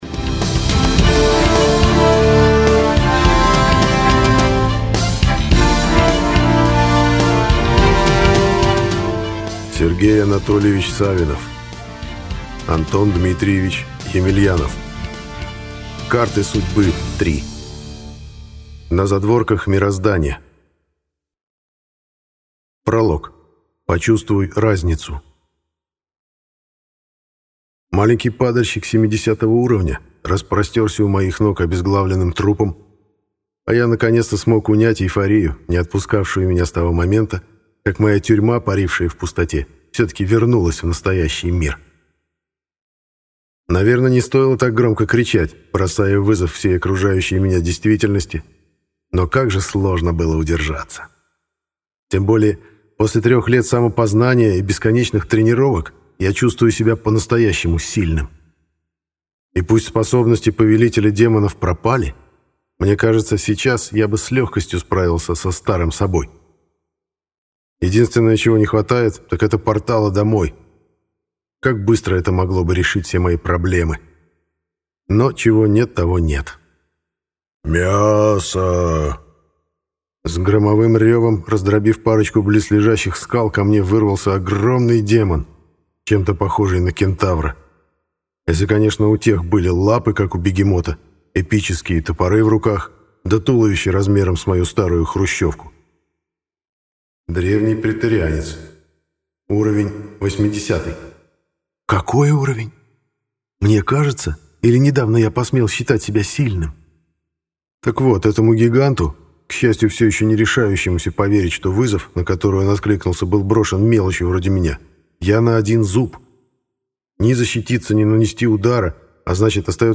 Aудиокнига Карты судьбы 3.